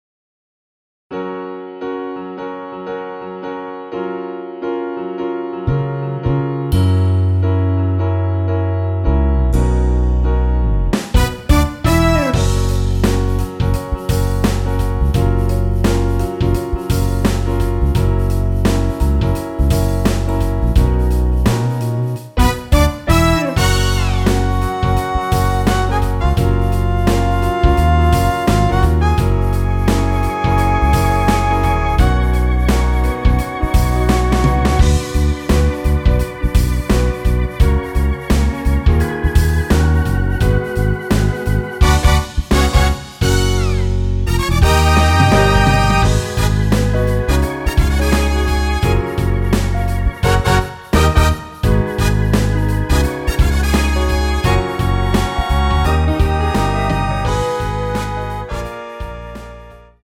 여성분들이 부르실수 있는키이며 이키가 높을경우 +4 MR을 이용하세요~
F#
앞부분30초, 뒷부분30초씩 편집해서 올려 드리고 있습니다.
중간에 음이 끈어지고 다시 나오는 이유는